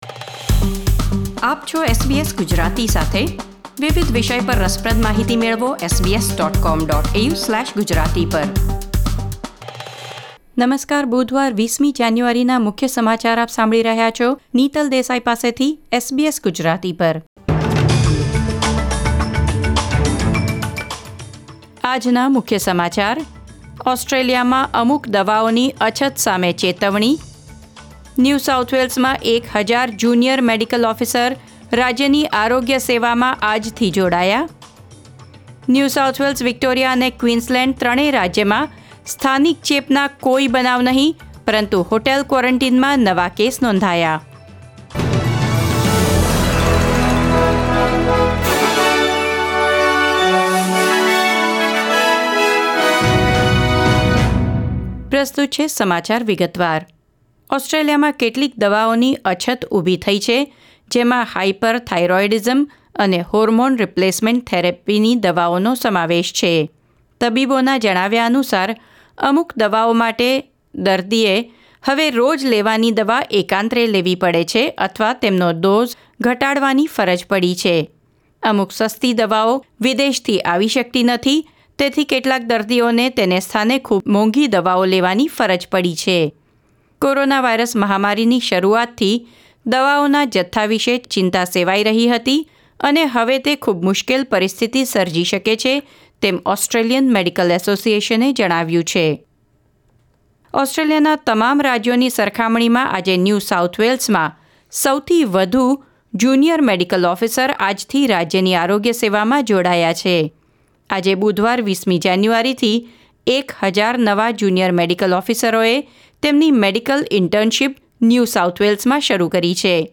૨૦ જાન્યુઆરી ૨૦૨૧ના મુખ્ય સમાચાર